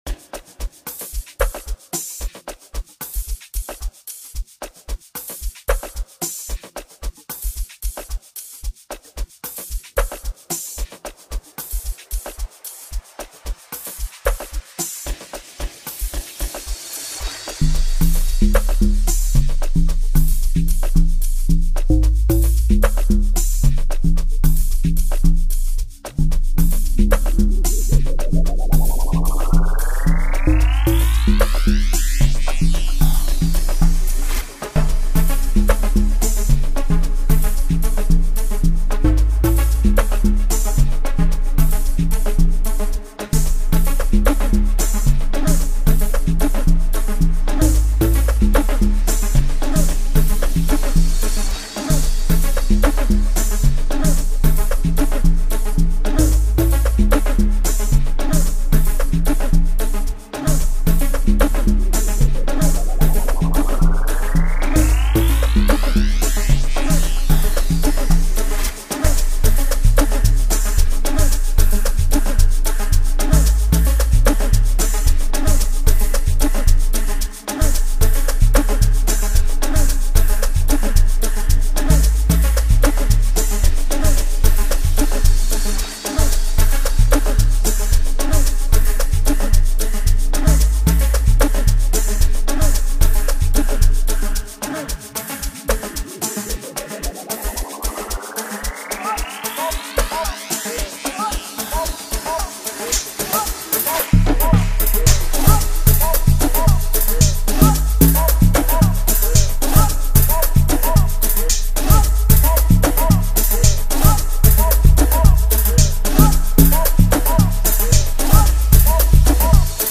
potential dancefloor scorcher